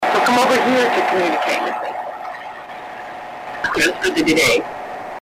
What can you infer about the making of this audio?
The response is amazingly clear.